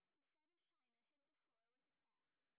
sp20_street_snr10.wav